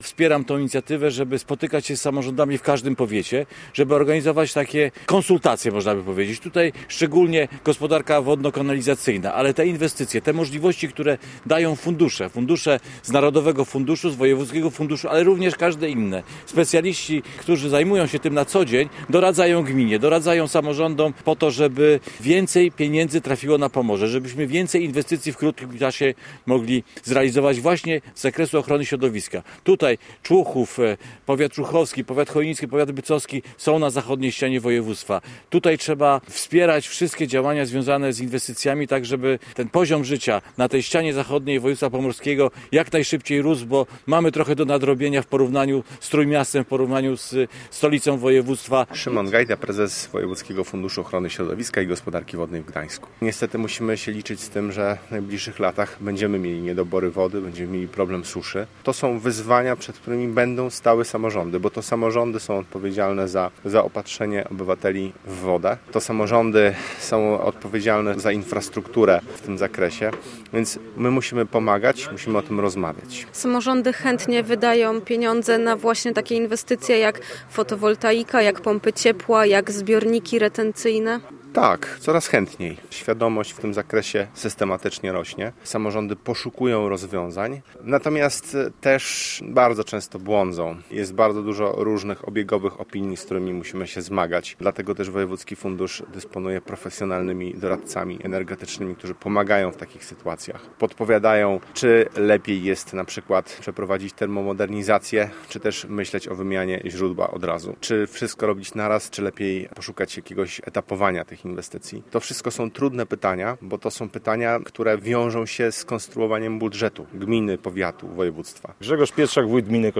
Inwestycje wodno-kanalizacyjne, ekologiczne źródła ciepła, termomodernizacje i ochrona środowiska były głównymi tematami konferencji w Koczale w powiecie człuchowskim, którą zorganizował pomorski Wojewódzki Fundusz Ochrony Środowiska i Gospodarki Wodnej.
Na spotkaniu obecny był między innymi wojewoda pomorski Dariusz Drelich, który podkreślał, że przeprowadzanie inwestycji w najdalszych zakątkach województwa jest bardzo ważne.
POSŁUCHAJ MATERIAŁU NASZEJ REPORTERKI: https